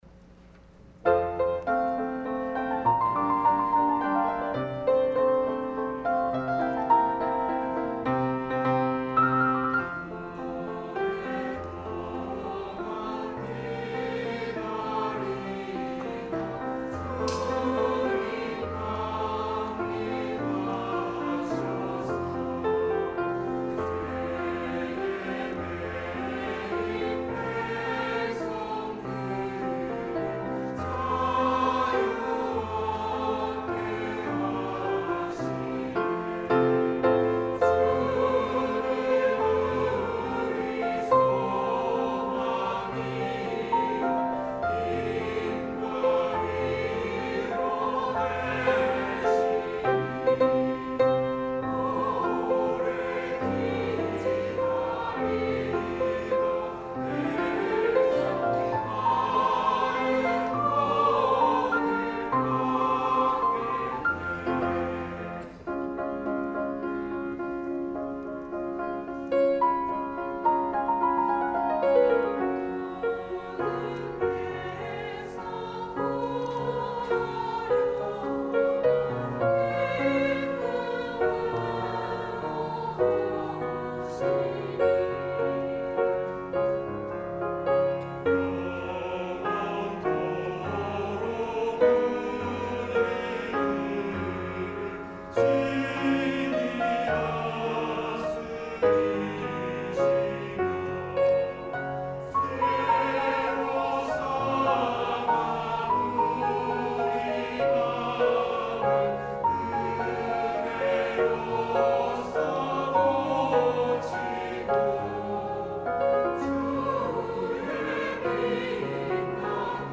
찬양 :: 141130 오랫동안 기다리던
" 오랫동안 기다리던 "- 시온찬양대